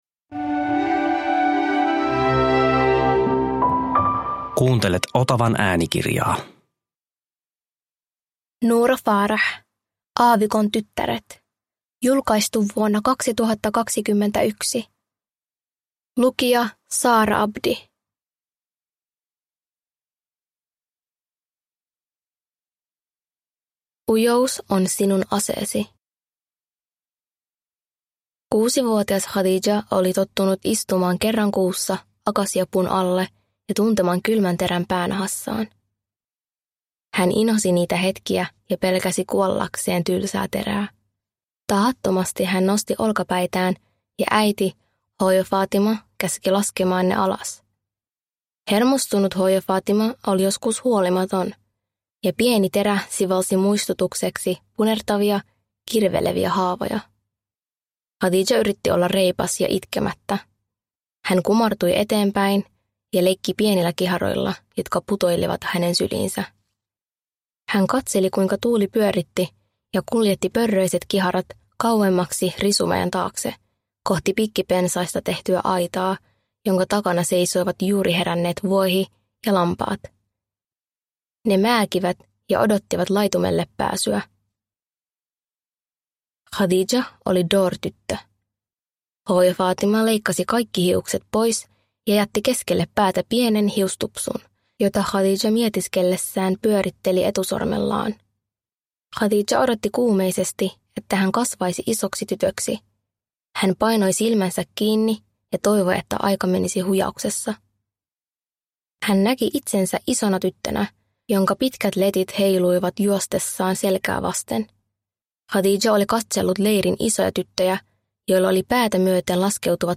Aavikon tyttäret – Ljudbok – Laddas ner